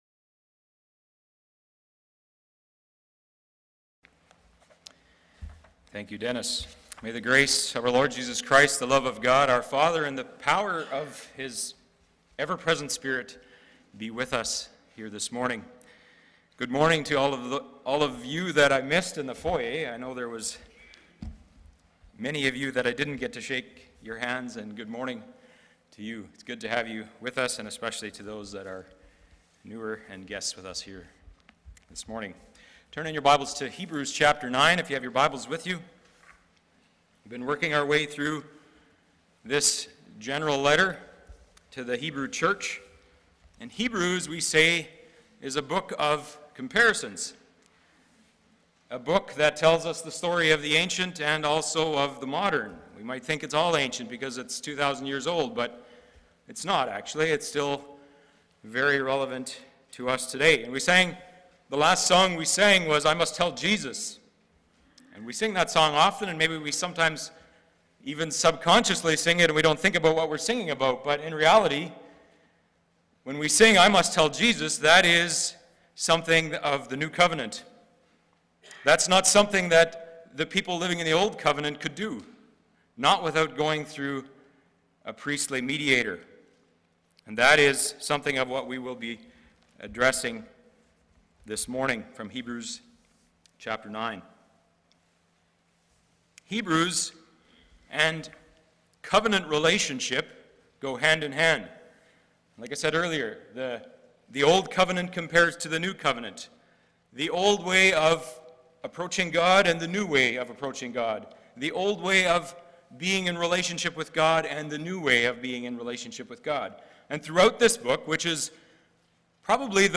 Passage: Hebrews 9:1-28 Service Type: Sunday Morning « Church Bible Study